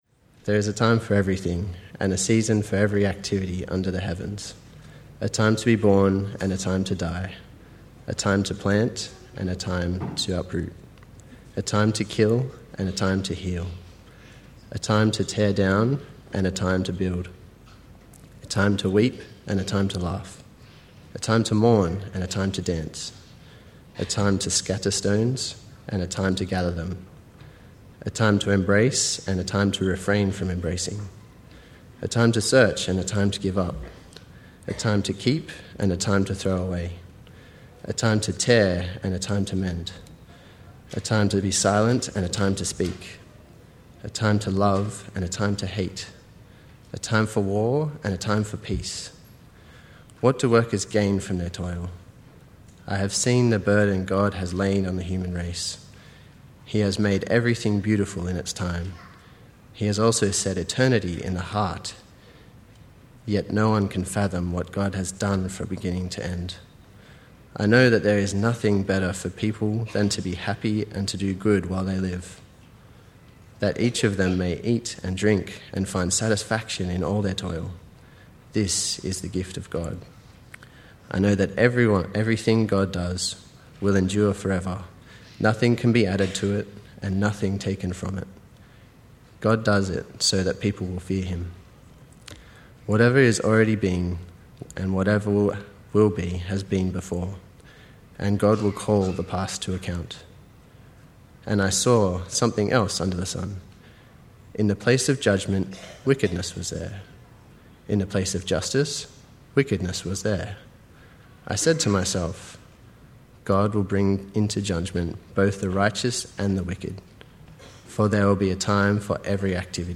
Sermon – A Festal Garment (Ecclesiastes)